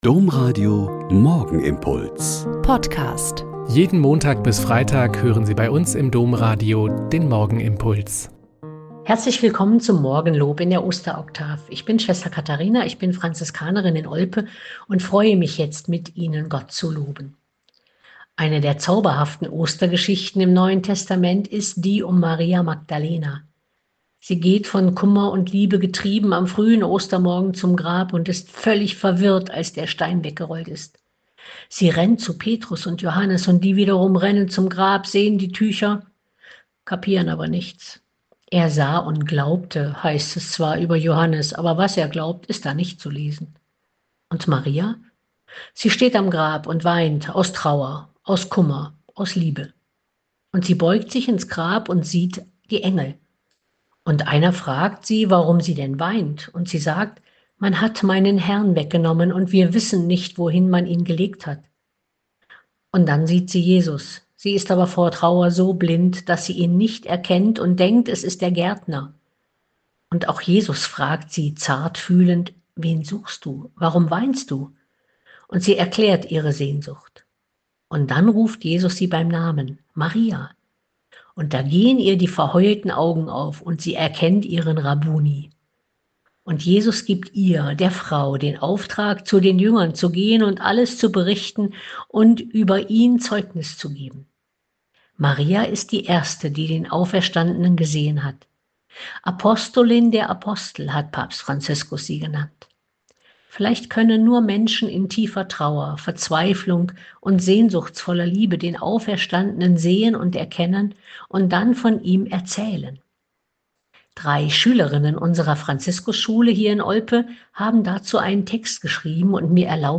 Lk 9,11b-17 - Gespräch